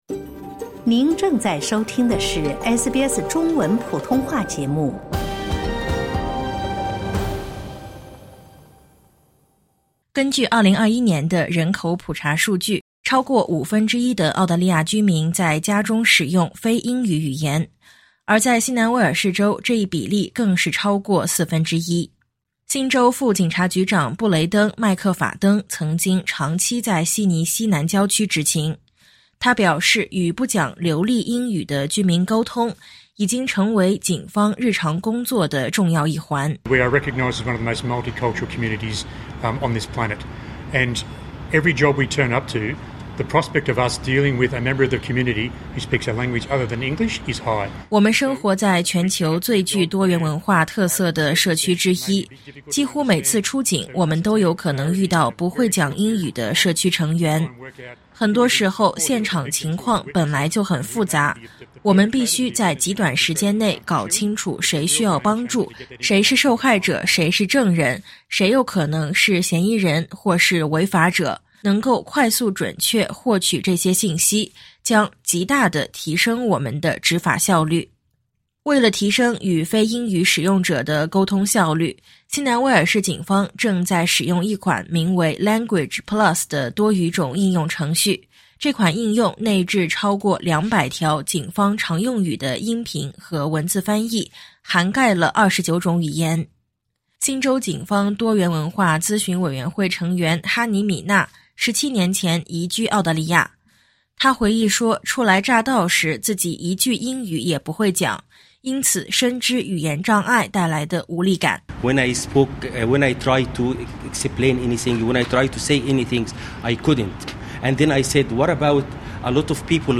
如今，新南威尔士州警方推出一项全新语言技术，帮助警员打破沟通障碍，更好地服务多元文化社区。点击音频，收听详细报道。